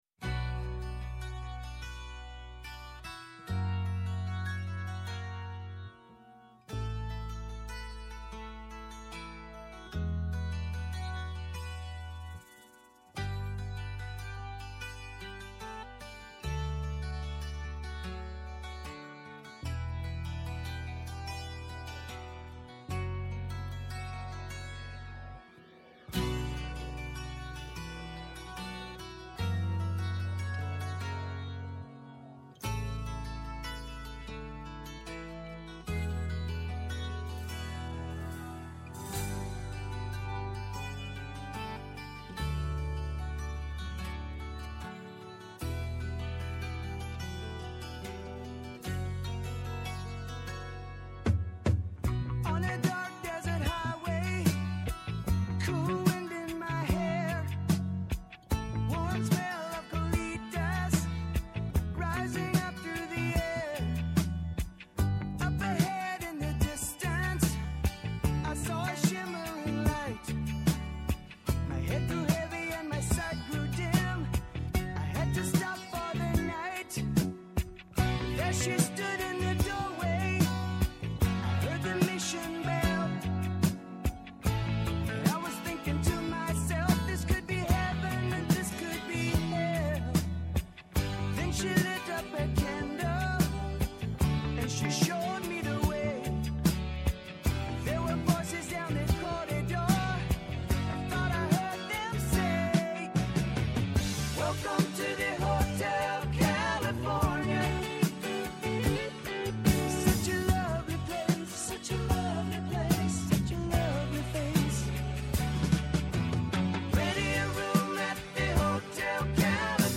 -Ο Χρήστος Γιαννούλης, Βουλευτής ΣΥΡΙΖΑ
Συνεντεύξεις